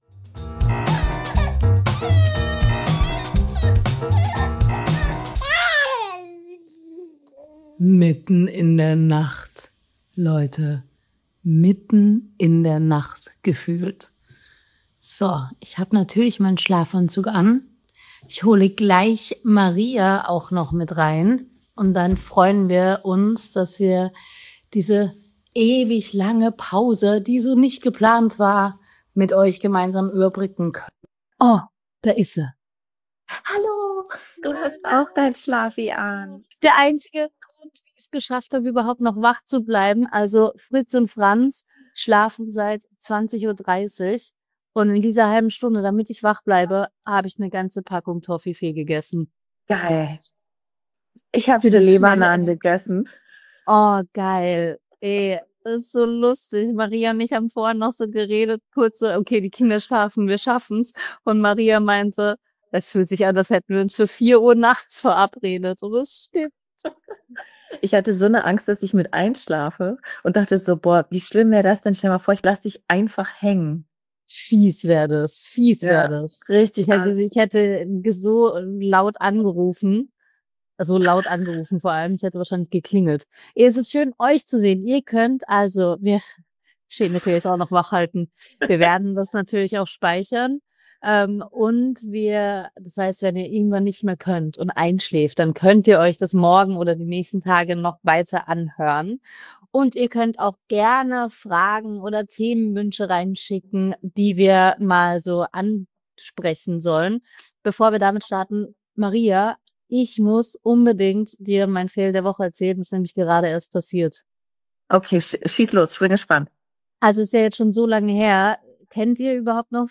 Also so halb...dreiviertel... nja zumindest mit dem Telefon...
Real Talk im Instagram Live Podcast, Chaos-Stories & ganz viel „muttivierend ehrlich“.